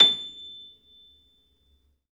53d-pno25-A5.wav